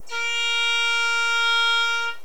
Wir hören also ein im 1. Register (Mode 1) geblasenes Bb3 ohne Grundfrequenz, ohne die Duodezime und ohne alle weiteren ungeraden Vielfachen der Grundfrequenz.
Die geradzahligen Partialtöne eines Klarinettentones
Heraus gekommen ist ein gut hörbares Bb4.
Was mir gerade auffällt: der gefilterte Klarinttenton klingt immer noch wie eine Klarinette!
klari-harm_cut.wav